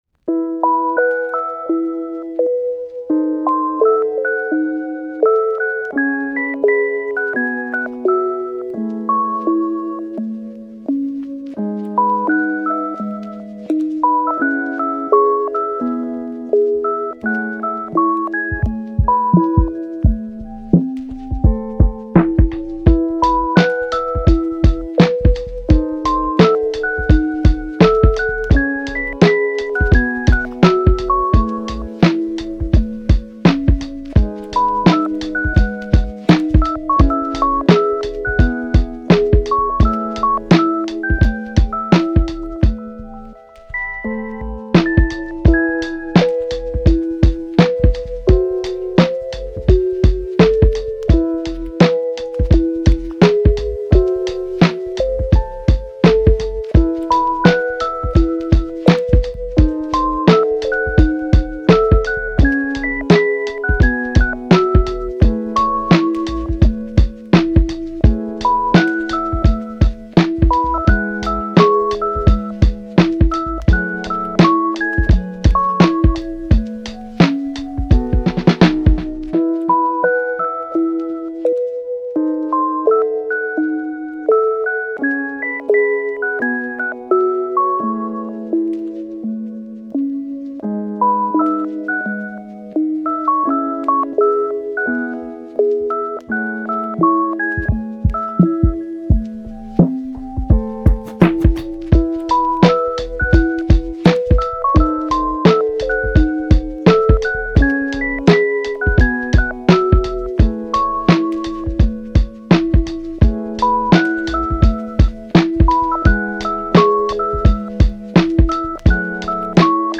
チル・穏やか
メロウ・切ない